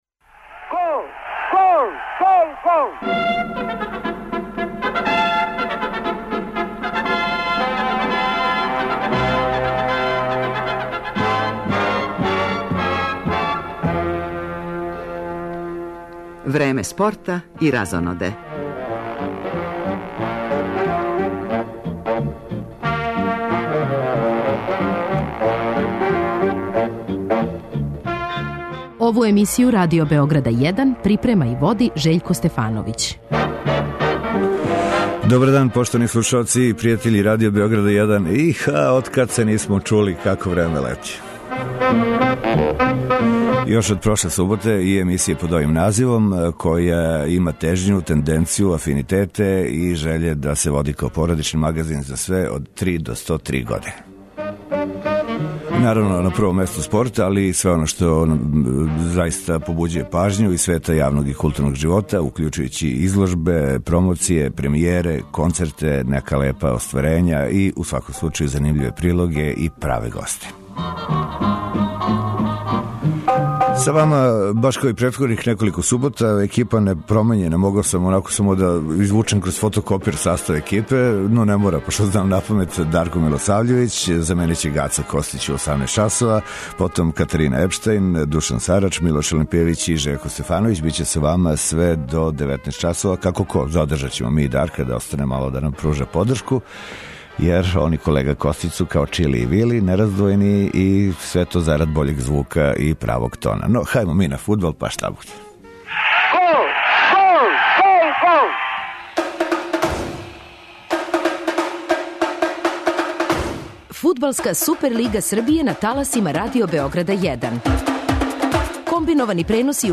Породични магазин,уз остале садржаје, негује и традицију комбинованих преноса нашег првенства, па ћемо у првом делу емисије испратити финиш већине утакмица овог кола, док ћемо од 18 часова, с популарне Маракане, слушати јављања репортера са утакмице Црвена Звезда - Смедерево.